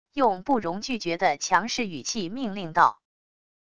用不容拒绝的强势语气命令道wav音频